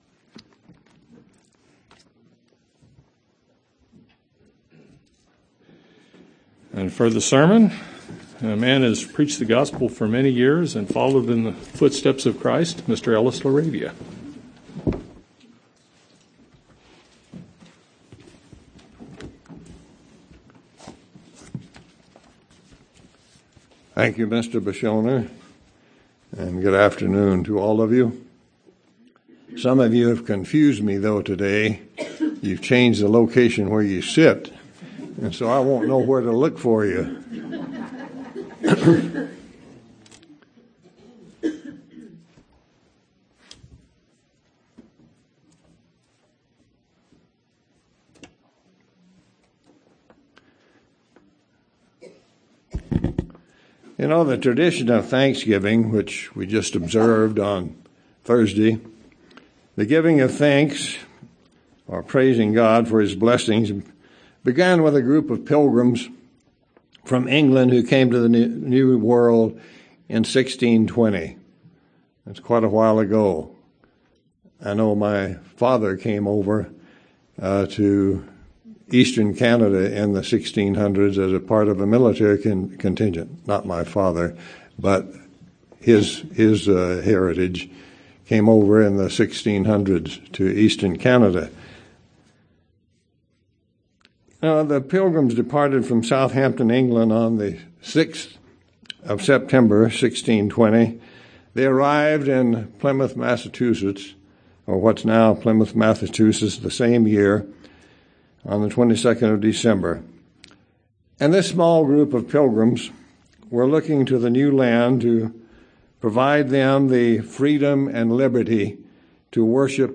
Given in Tucson, AZ
UCG Sermon Studying the bible?